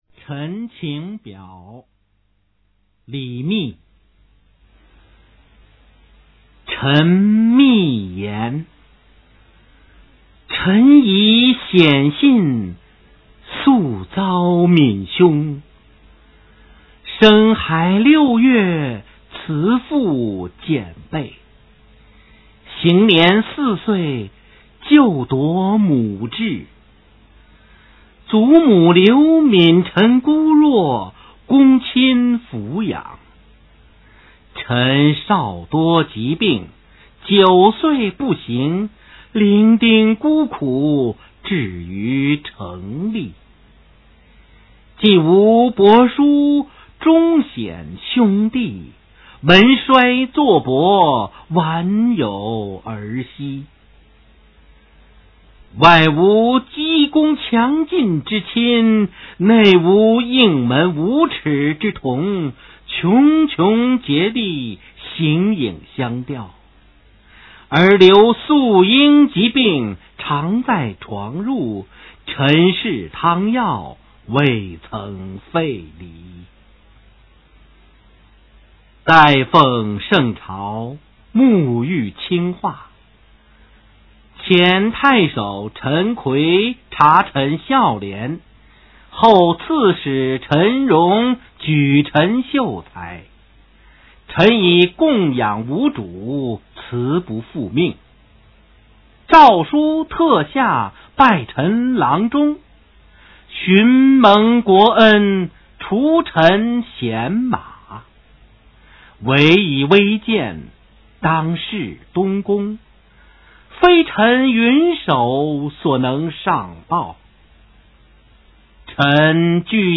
《陈情表》朗读